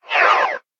trash-empty.ogg